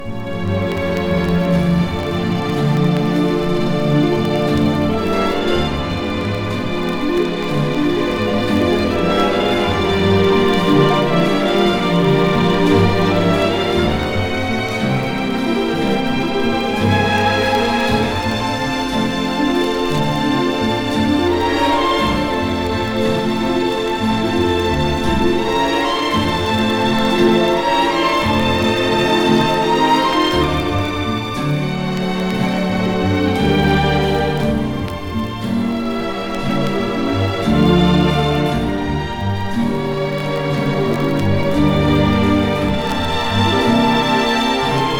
美しい旋律と優しいムードに包まれる爽やかな良盤です。